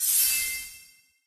bp_snout_coin_fly_04.ogg